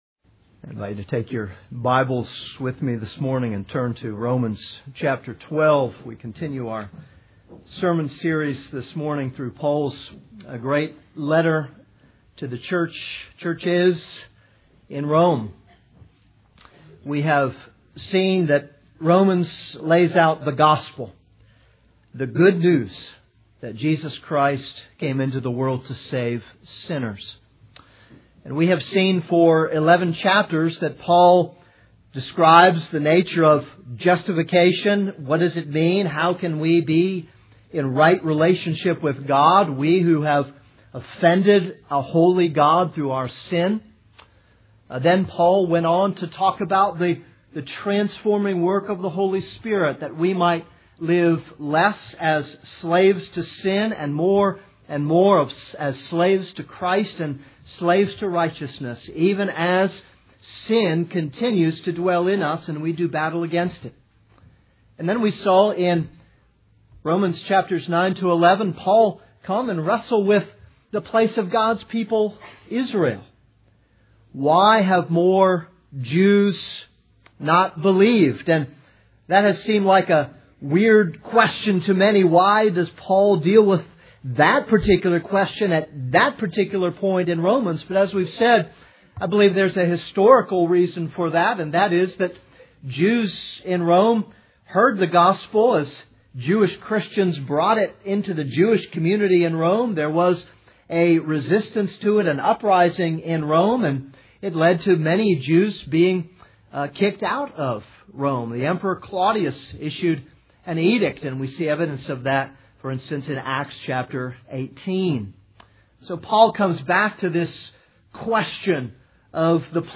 This is a sermon on Romans 12:9-16.